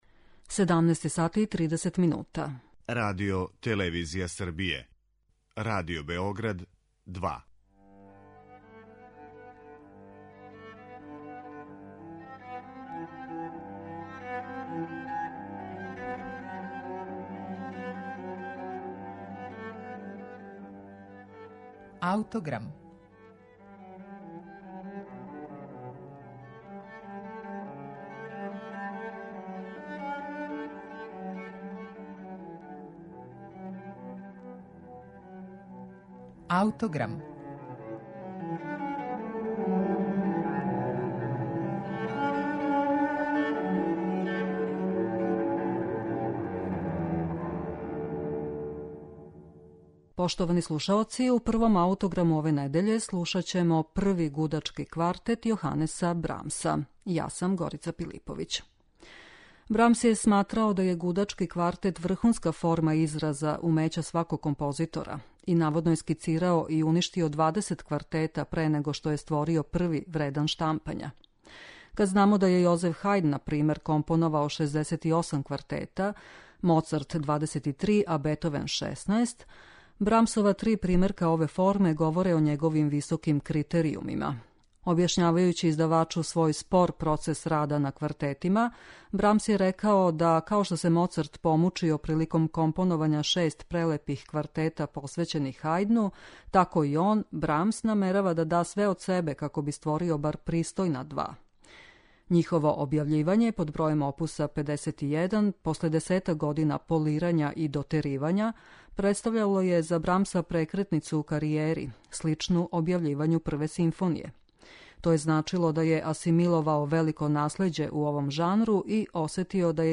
гудачки квартет